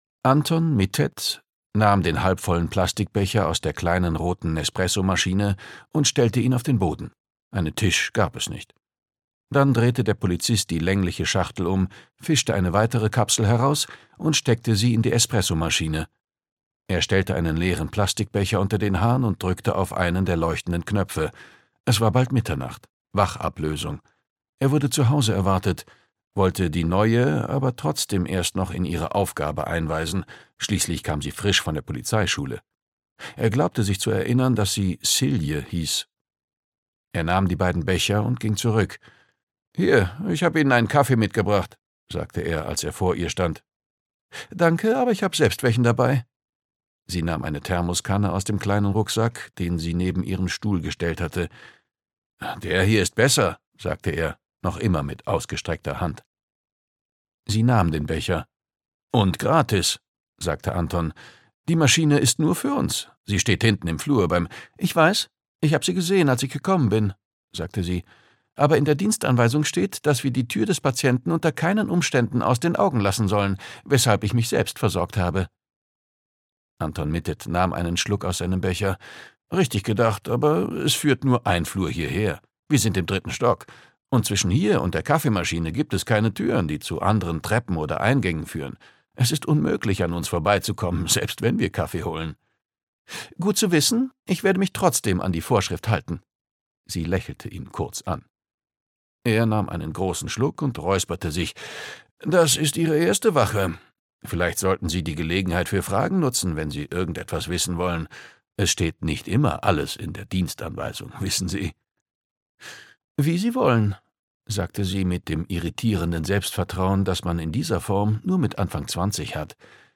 Koma (Ein Harry-Hole-Krimi 10) - Jo Nesbø - Hörbuch